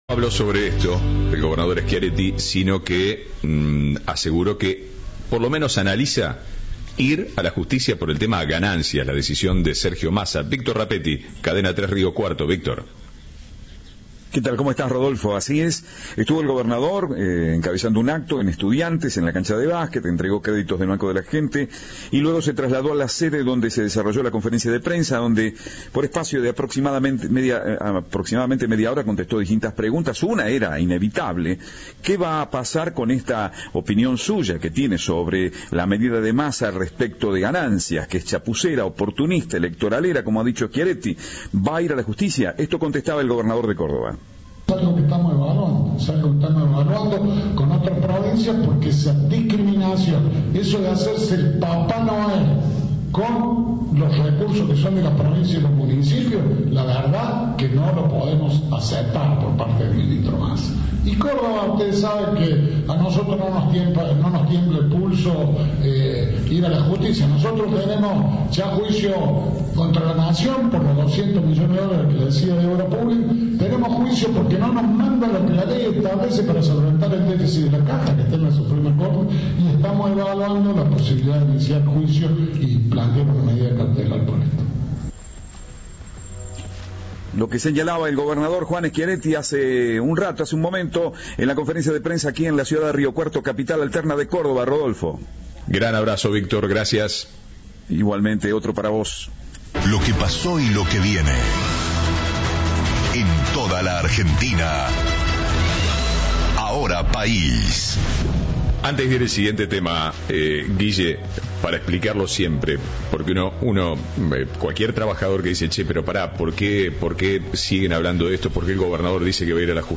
Luego, se trasladó a la sede donde se desarrolló una conferencia de prensa y respondió distintas preguntas por media hora.
Informe